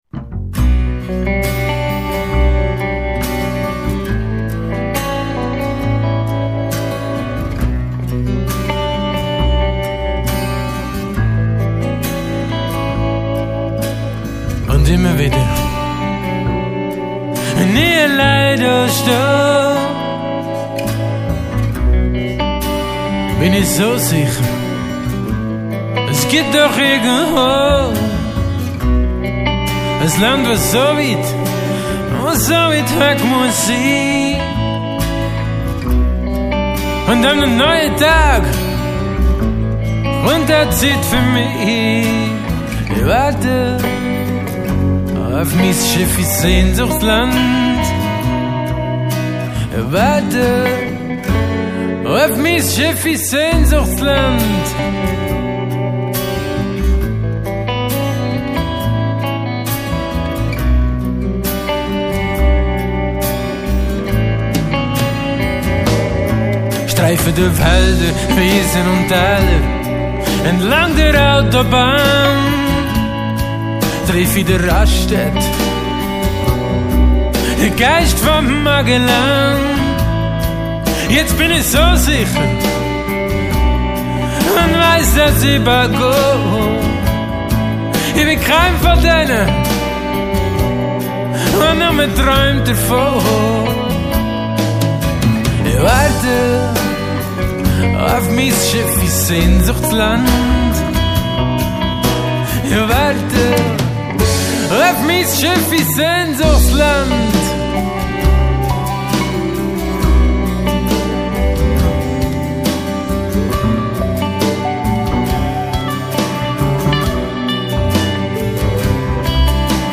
alles andere Homerecording
Schlagzeug
Bass
E-Gitarren
Gesang, akustische Gitarren